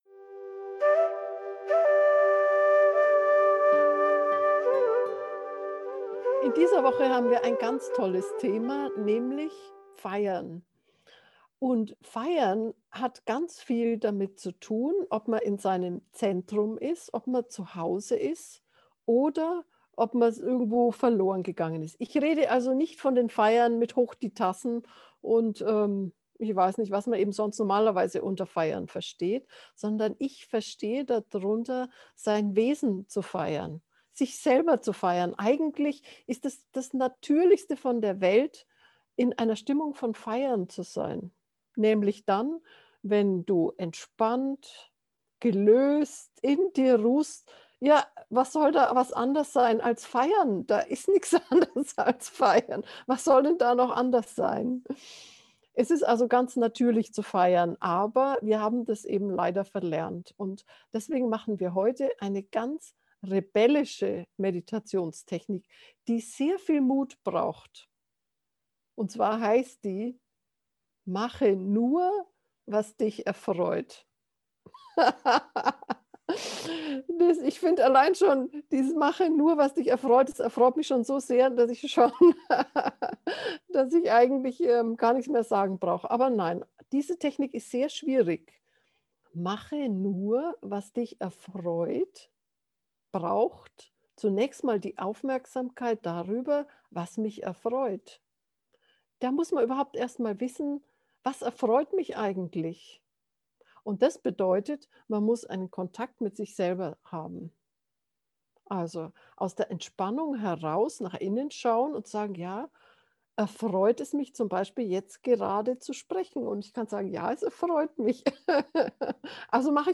Geführte Meditationen Folge 86: Feiern!